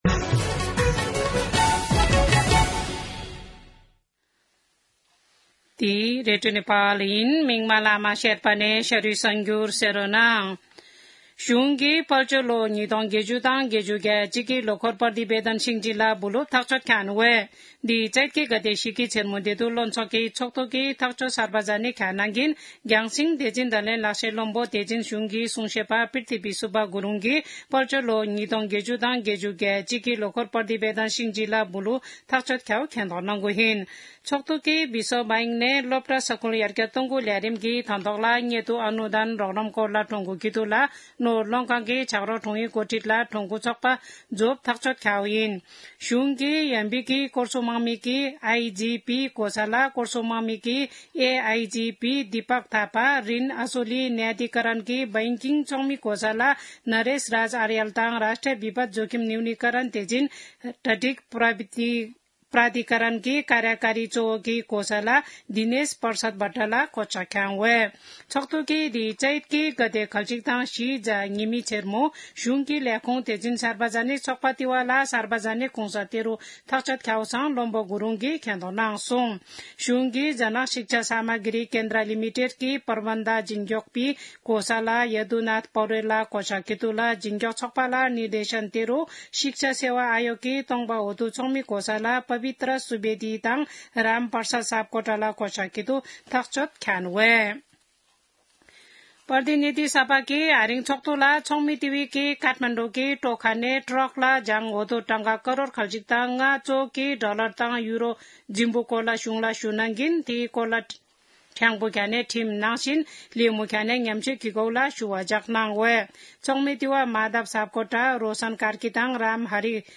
शेर्पा भाषाको समाचार : ६ चैत , २०८१
4-pm-Sherpa-News-12-06.mp3